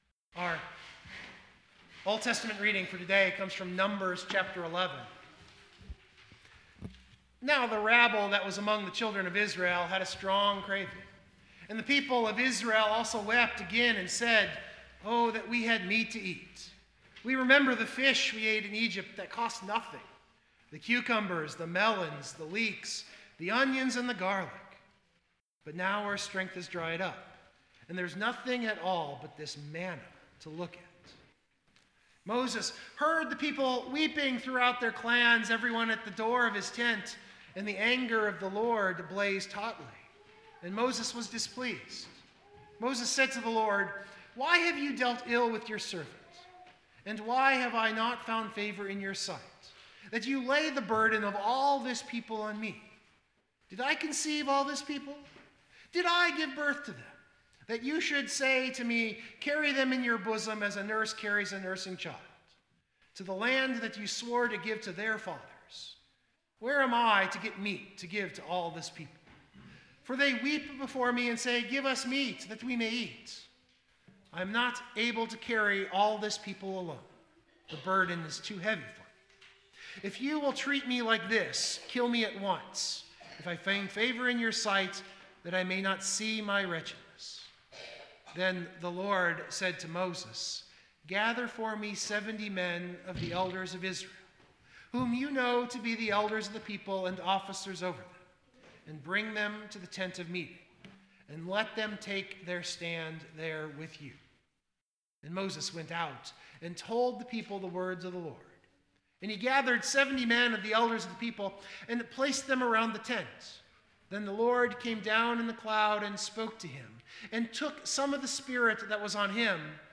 The sermon attempts to have an artistic flair. Parts of a one man show, the remembrances of Peter.
I left in the recording our hymn of the day which is in my top 5 hymns.
Mainly because it is a little slow do develop and has a strong poetic structure.